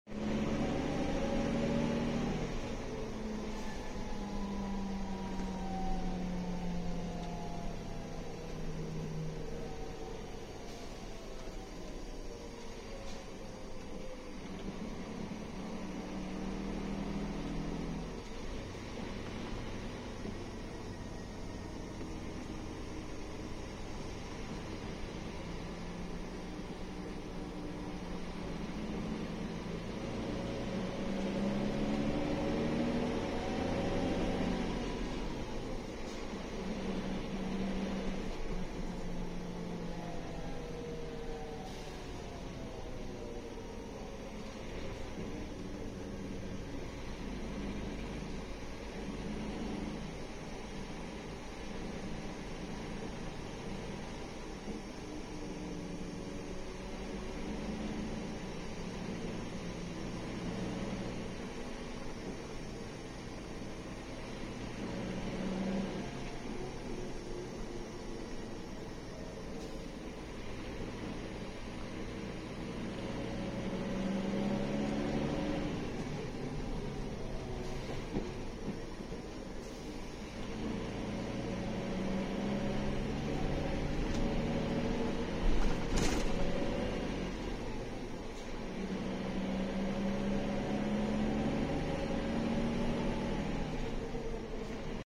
Scania K360IB x DC13 ASMR Onboard GV Florida S0020 Sa mga lovers ng retarder sound saka turbo whistle, pakinggan natin sya with earphones😁